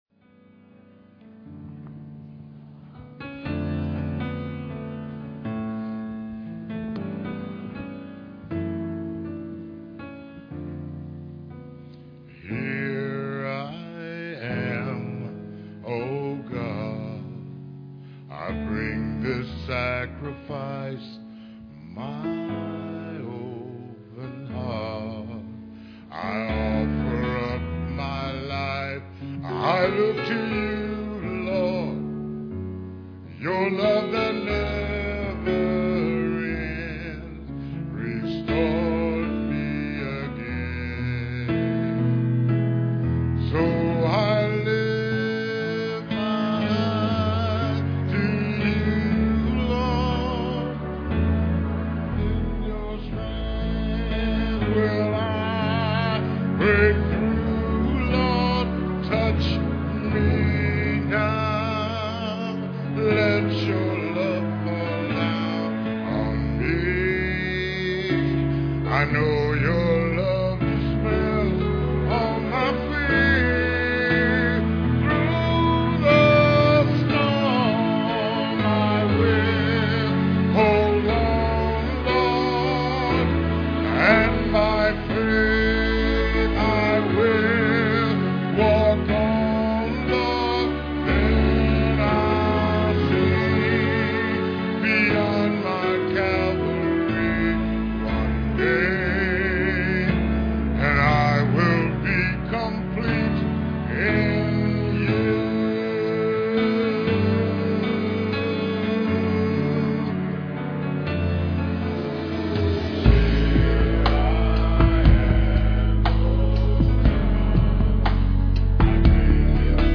Piano and organ duet